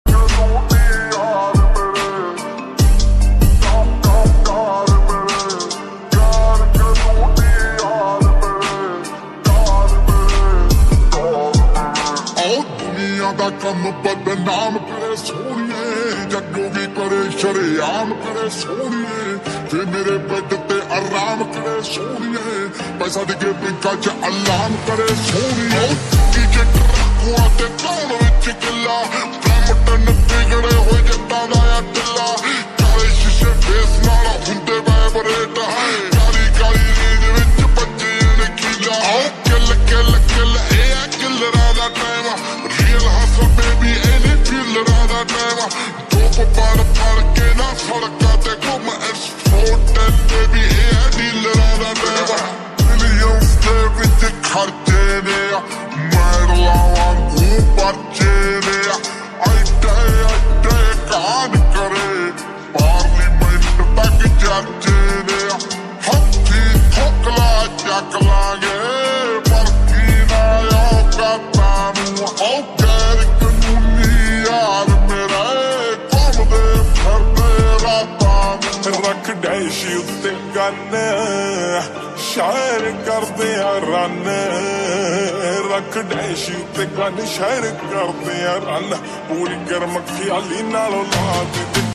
SLOWED X MUSIC